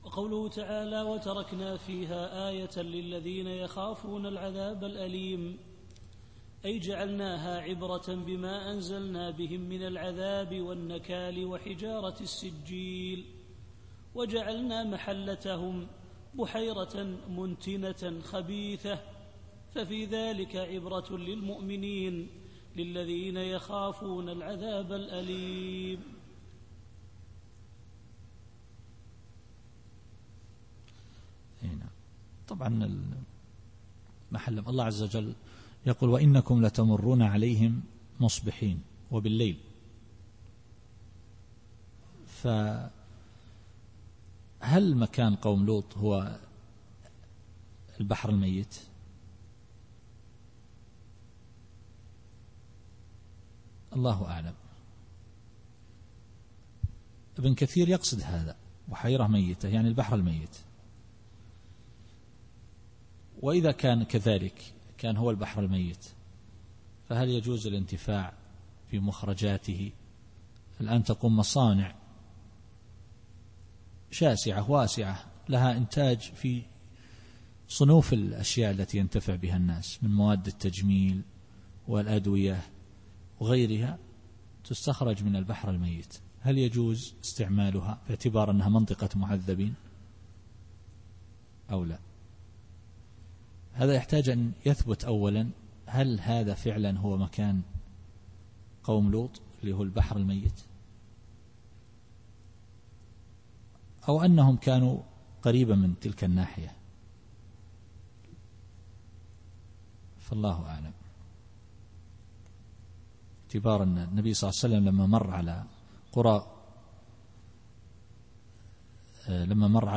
التفسير الصوتي [الذاريات / 37]